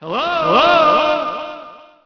echo_hello.wav